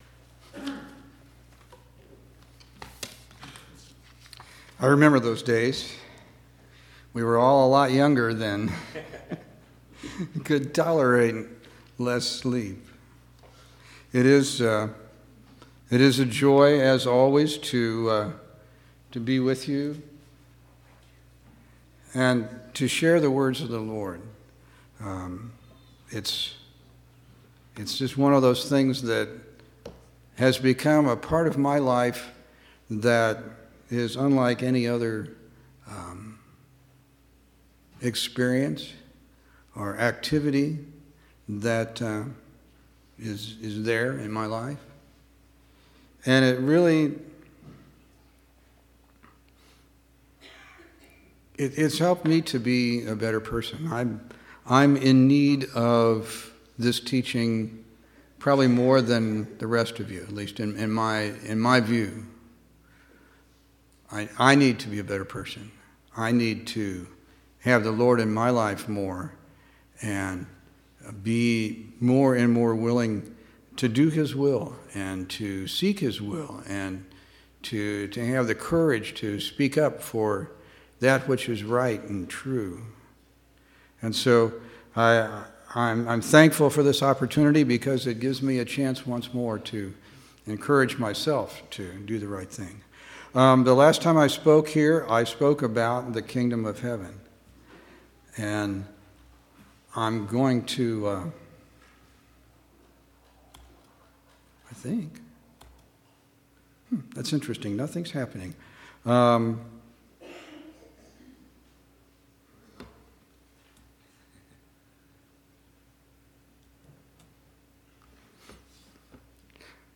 12/16/2012 Location: Temple Lot Local Event